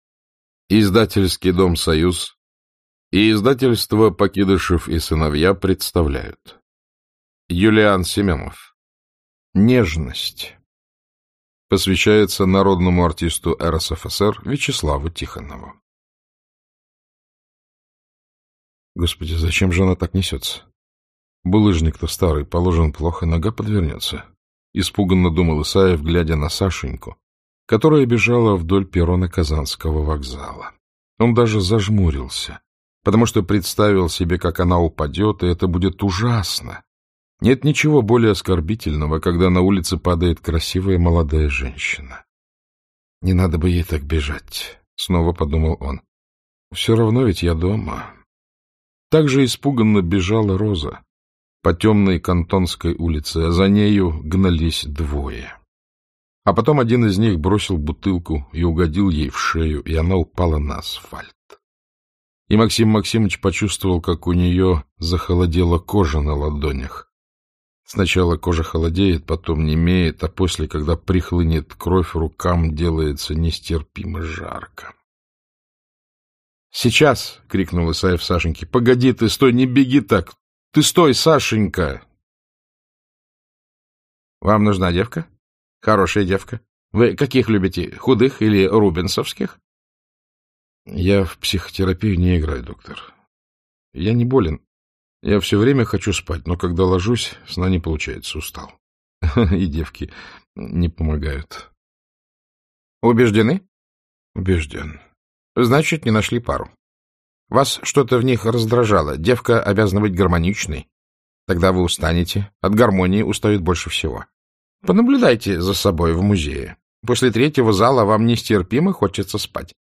Аудиокнига Нежность | Библиотека аудиокниг
Aудиокнига Нежность Автор Юлиан Семенов Читает аудиокнигу Александр Клюквин.